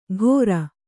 ♪ ghōra